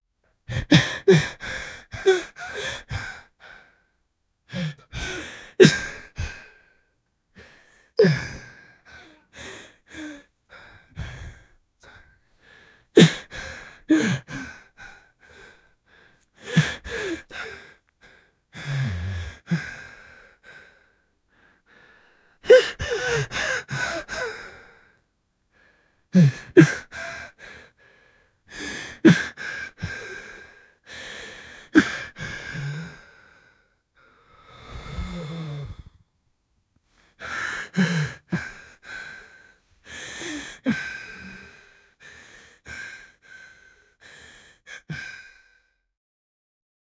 soft-quiet-young-man-cryi-m2mudf5e.wav